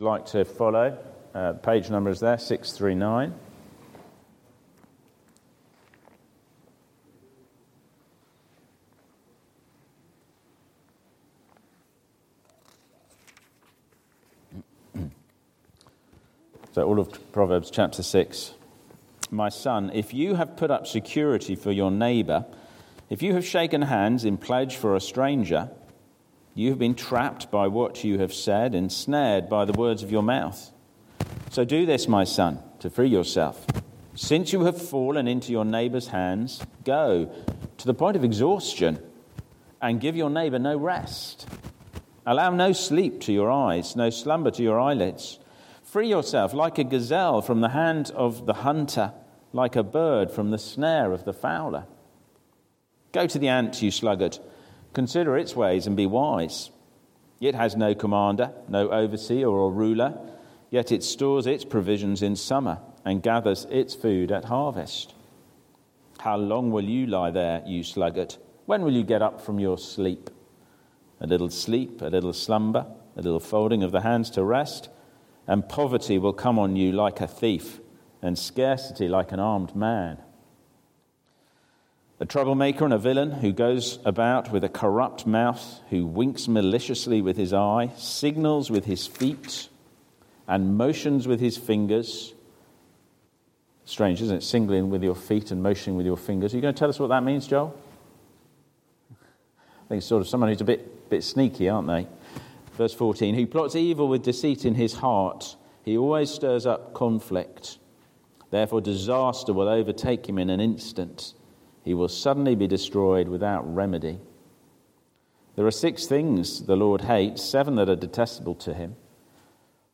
Sermons – Dagenham Parish Church
Luke 1 Service Type: Sunday Morning